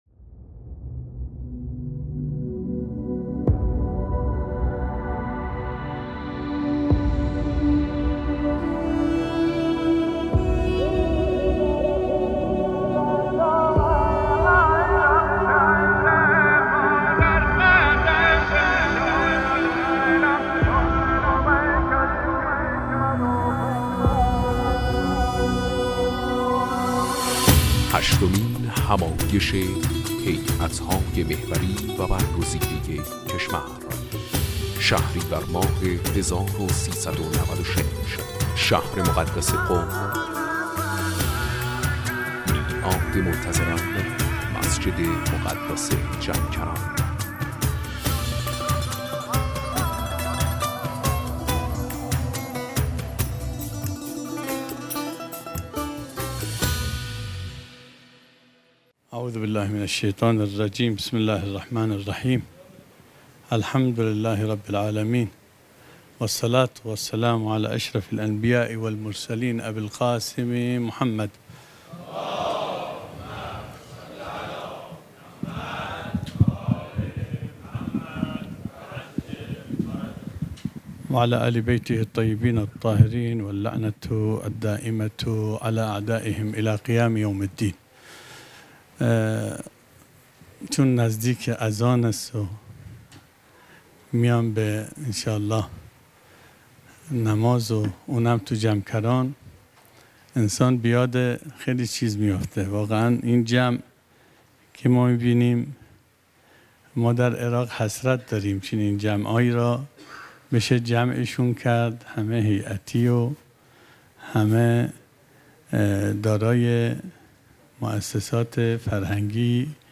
سخنرانی
هشتمین همایش هیأت‌های محوری و برگزیده کشور | شهر مقدس قم - مسجد مقدس جمکران